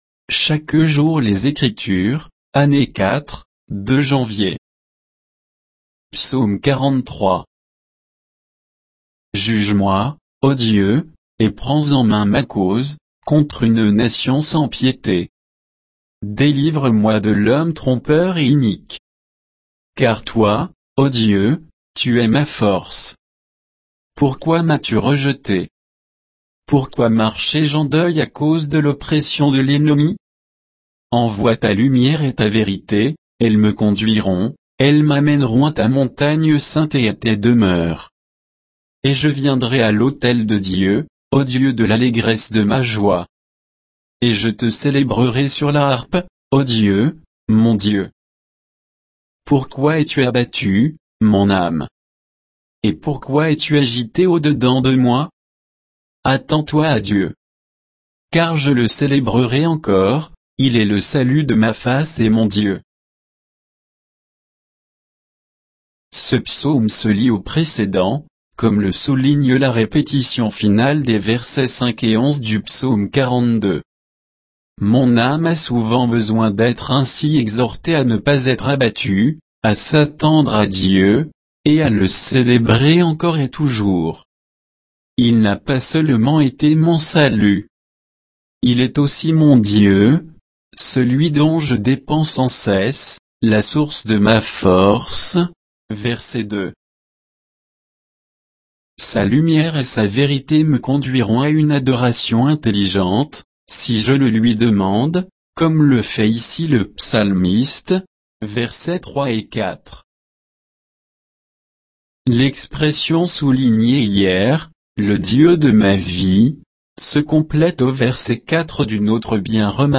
Méditation quoditienne de Chaque jour les Écritures sur Psaume 43